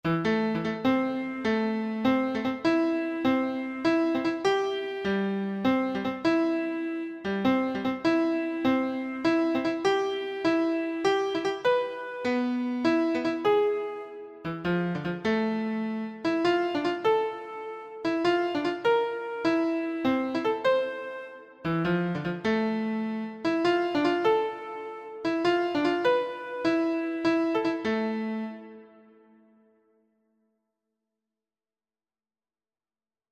Keyboard version